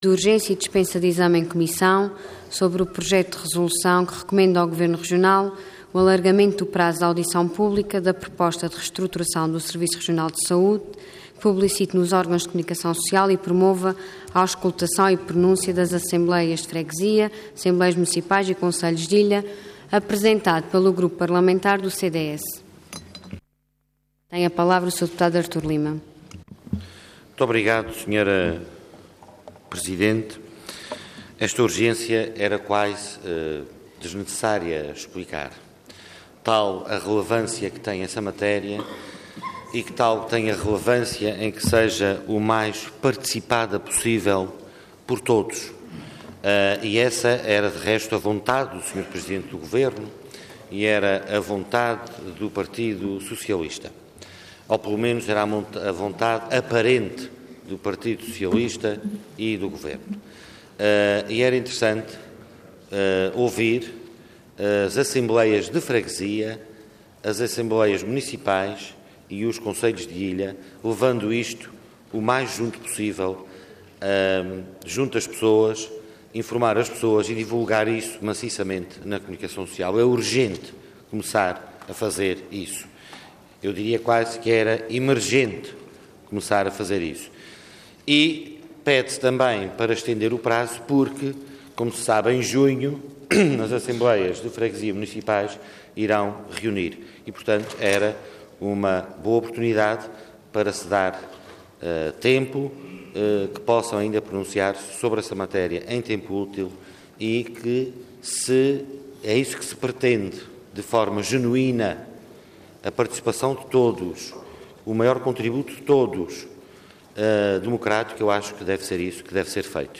Assembleia Legislativa da Região Autónoma dos Açores
Intervenção
Artur Lima
Deputado